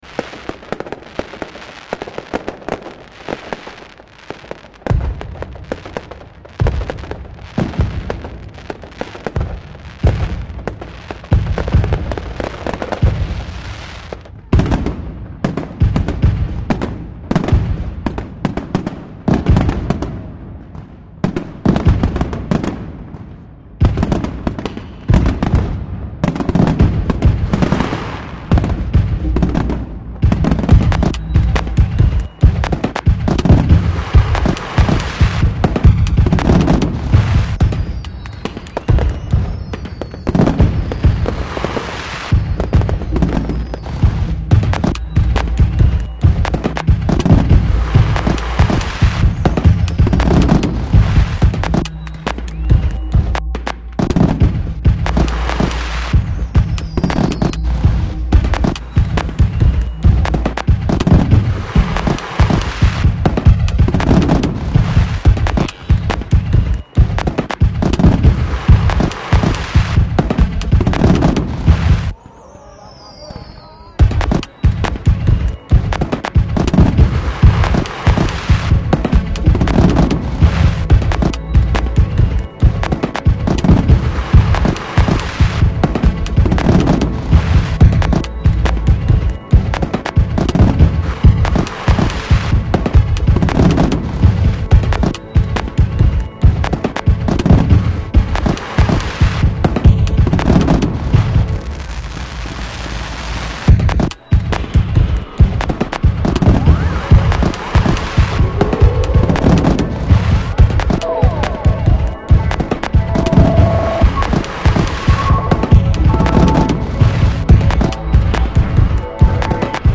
Fireworks display reimagined by Cities and Memory. Every single sound in this piece - from kick drums and hat sounds to drone pulses and atmospheric lead sounds - derives directly from a field recording of New Year's Eve fireworks in Padova, Italy.